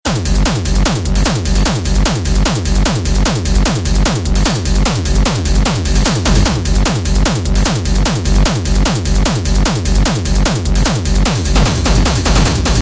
硬式低音环路1
Tag: 150 bpm Hardstyle Loops Drum Loops 2.16 MB wav Key : Unknown